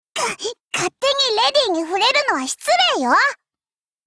贡献 ） 协议：Copyright，人物： 碧蓝航线:小可畏语音 您不可以覆盖此文件。